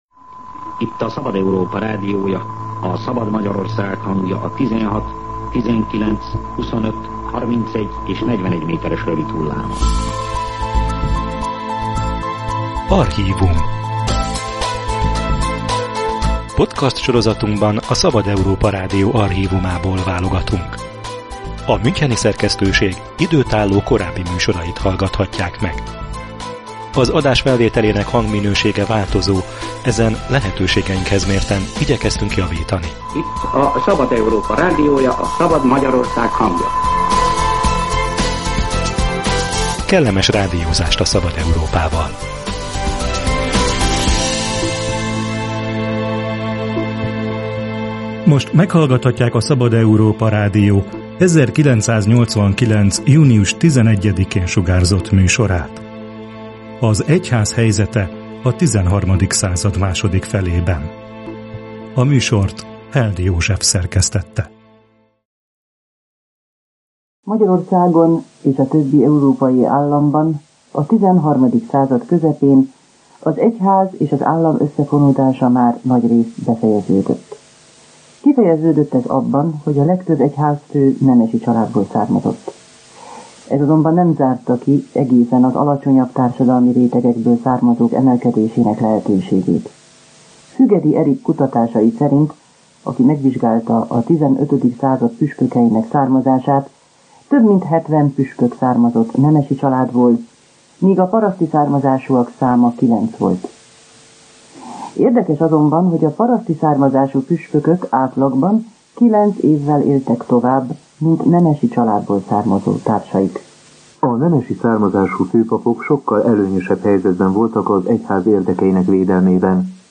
A XIII. század második felére megváltozott az egyházi vezetők kiválasztása, a főpapok közé bekerülhettek a paraszti családból érkezők. Ezt az átalakulást és ennek következményeit mutatja be a Szabad Európa Rádió 1989. június 11-én sugárzott műsora.